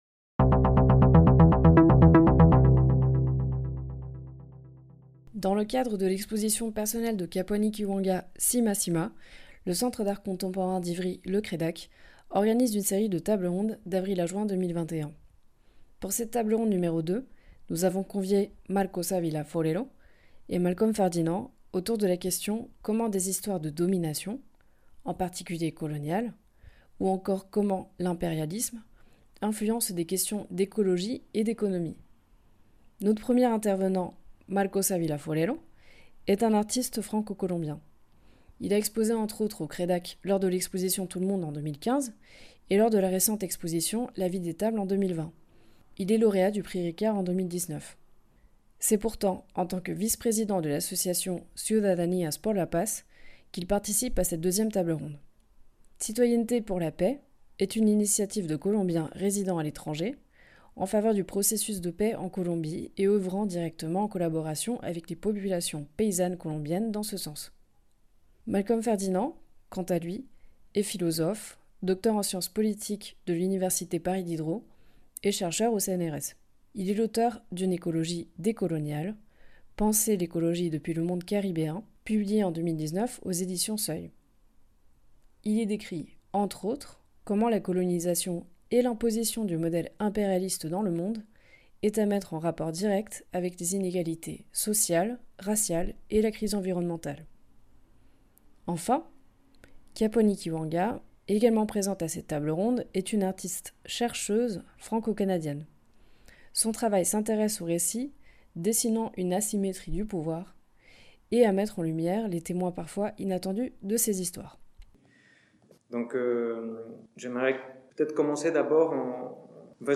TABLE RONDE Nº2